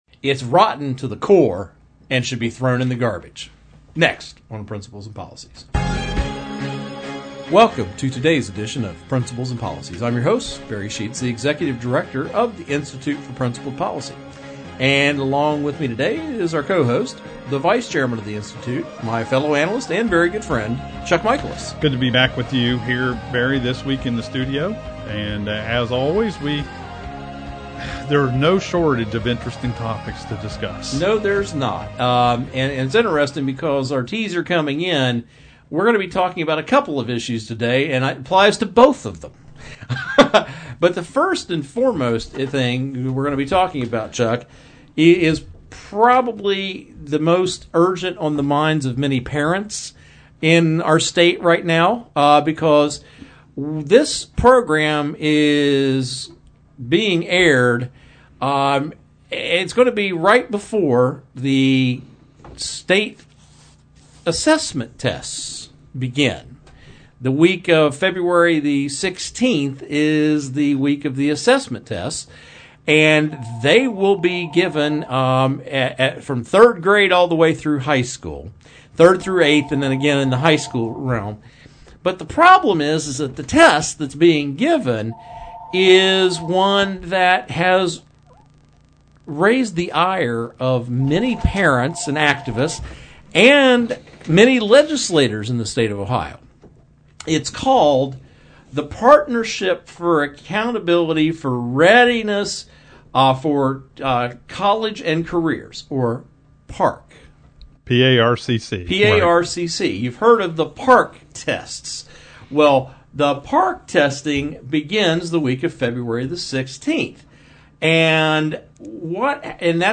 Our Principles and Policies radio show for Saturday February 14, 2015.